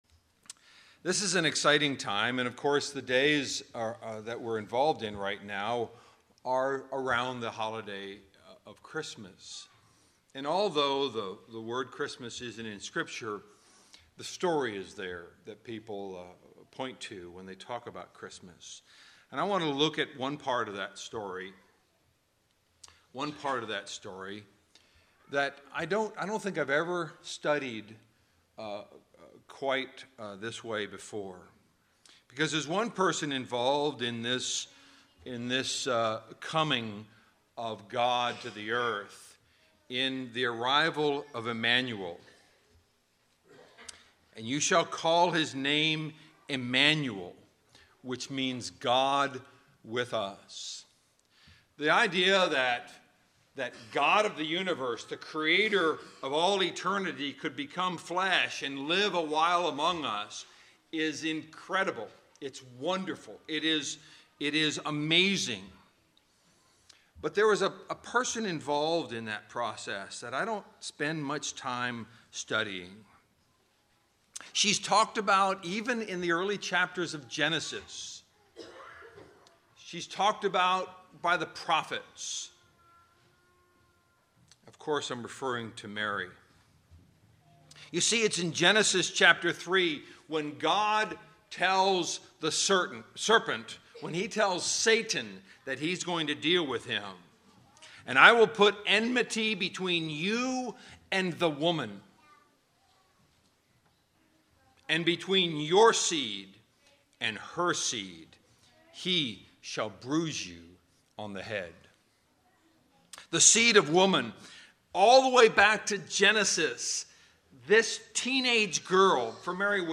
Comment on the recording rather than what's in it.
Valley church of Christ - Matanuska-Susitna Valley Alaska